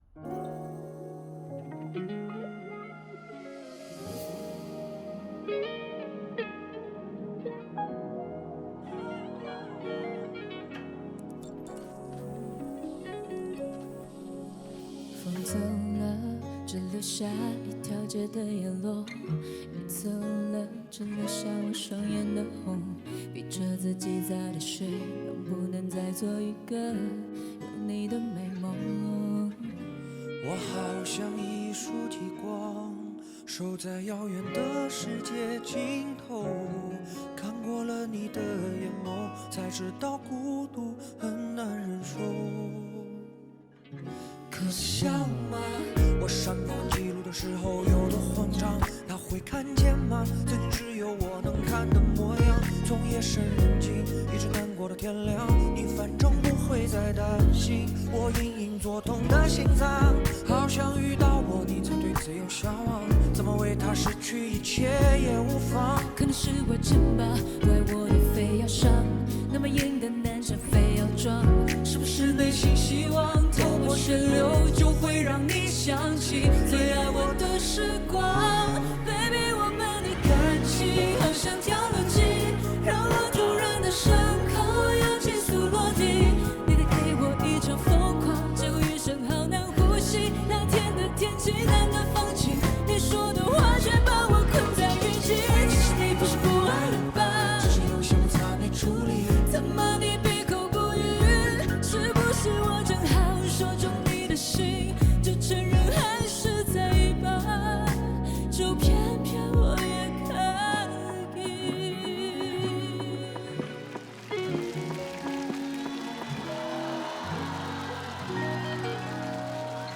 深情Rap碰撞宿命感和声 失重感与孤独感在这一刻完美交织！